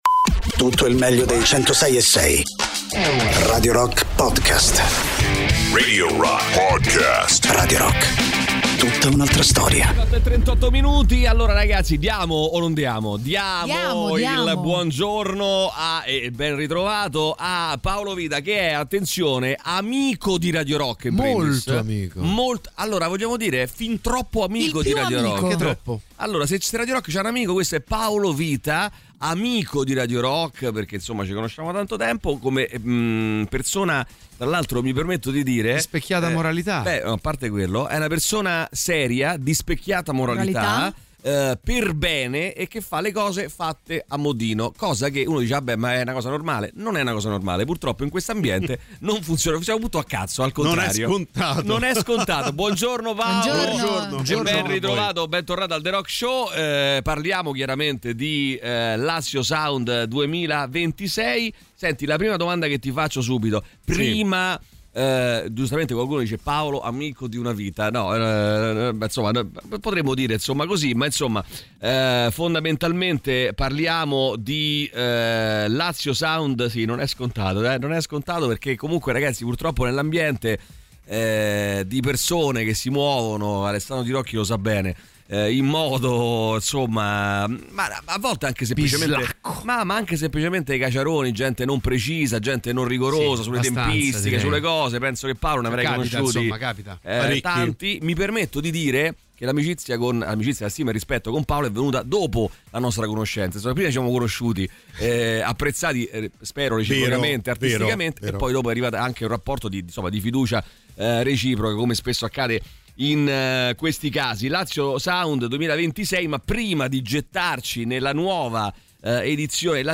Radio Rock FM 106.6 Interviste